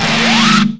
VEC3 Scratching FX